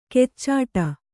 ♪ keccāṭa